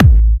VEC3 Bassdrums Trance 41.wav